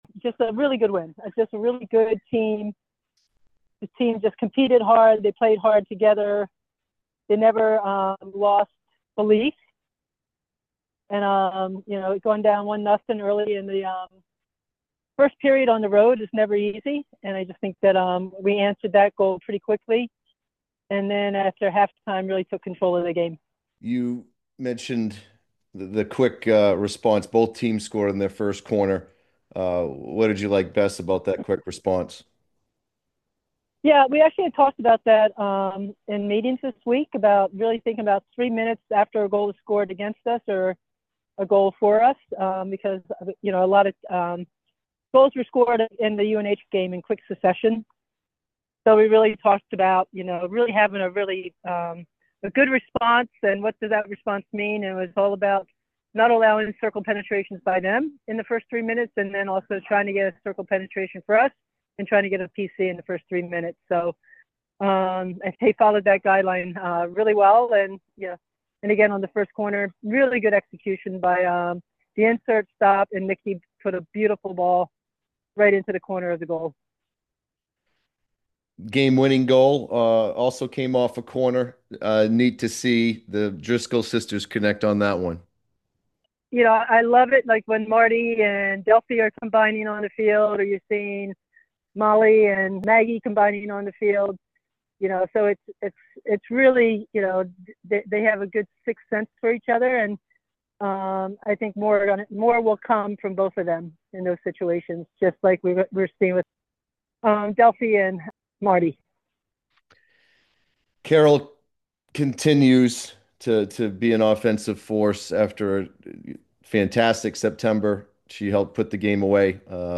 Field Hockey / Lehigh Postgame Interview